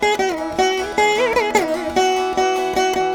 153  VEENA.wav